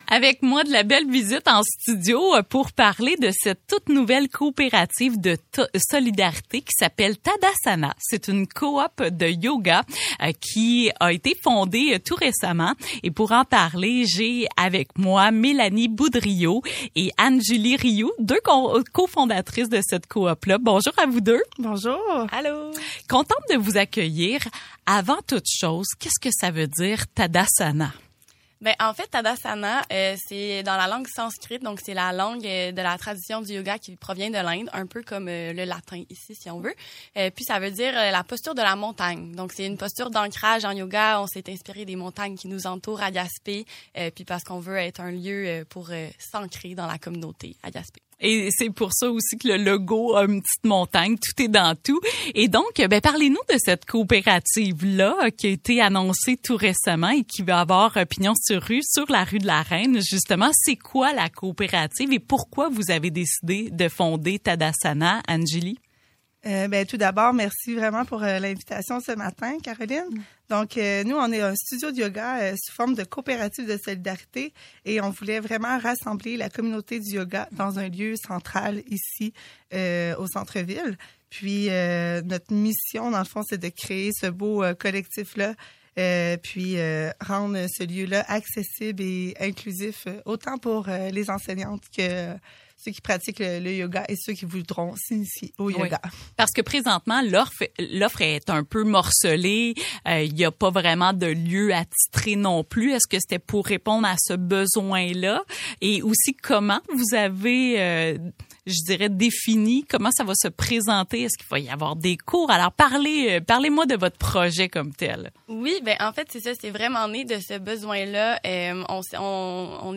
s’est entretenue avec deux des cinq co-fondatrices